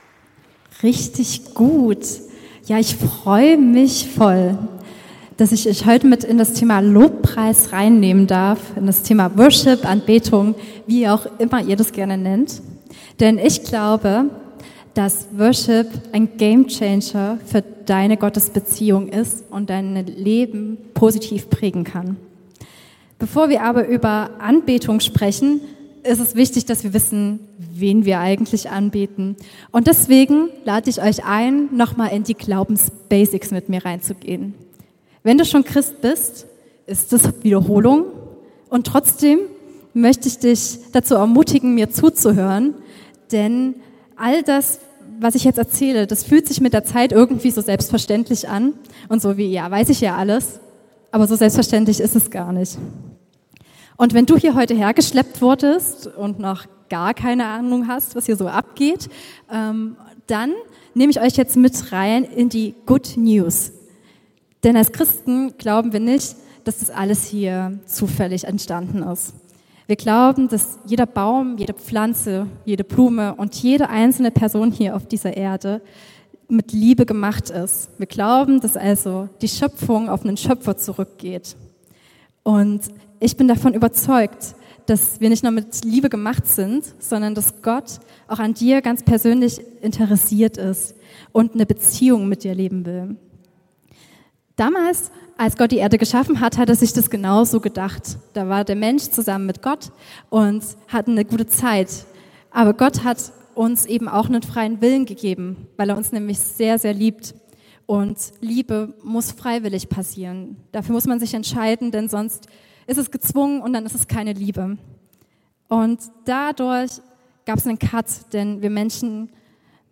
PROMISE Jugendgottesdienst
21:30 Uhr Jakobikirche Freiberg